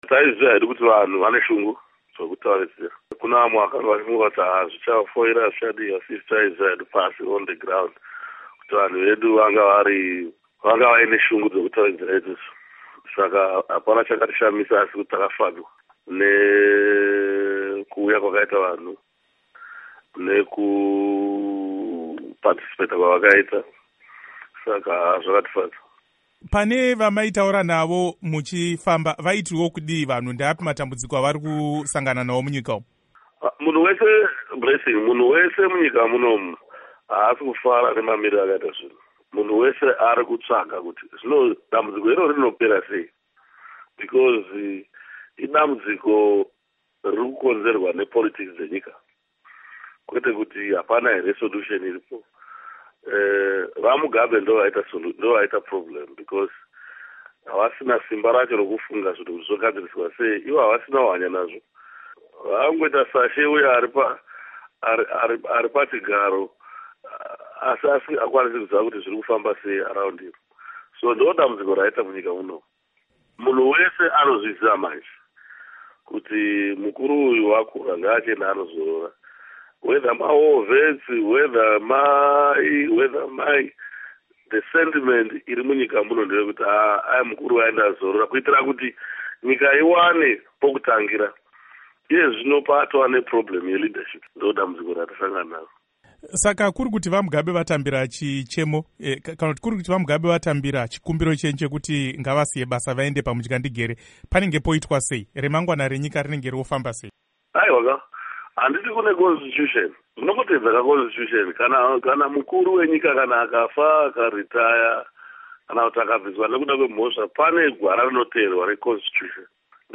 Hurukuro naVaMorgan Tsvangirai